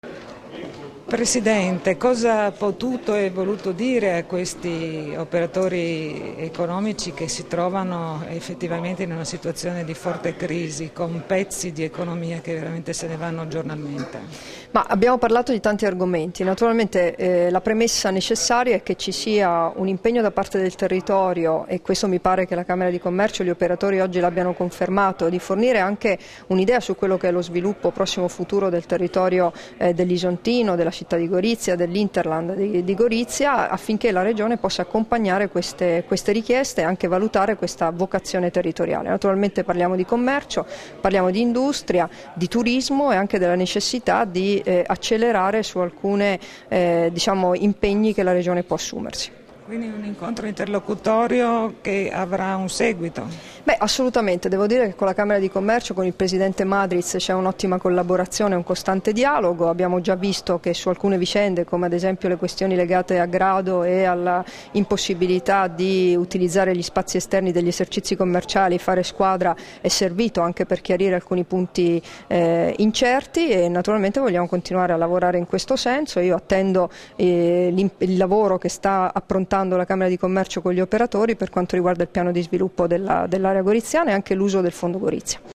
Dichiarazioni di Debora Serracchiani (Formato MP3) rilasciate a margine dell'incontro con il Consiglio della Camera di Commercio, a Gorizia il 3 marzo 2014 [1369KB]